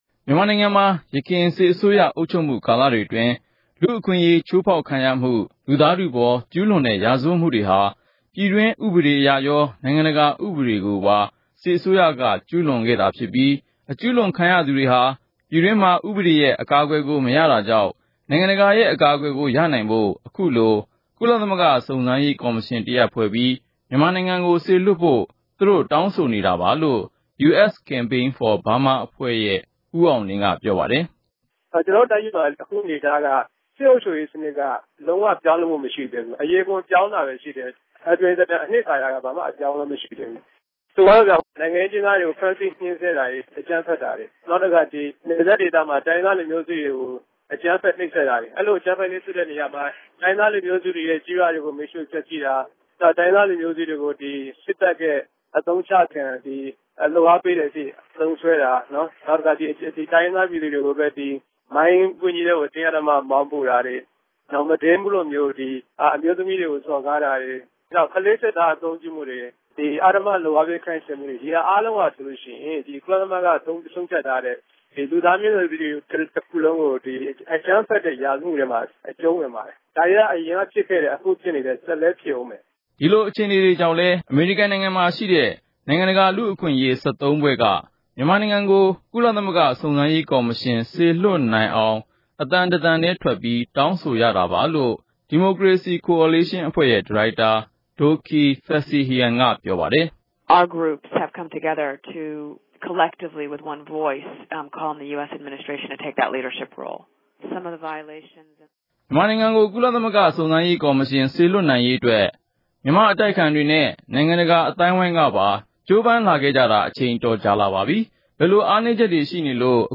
မေးမြန်းတင်ပြချက်။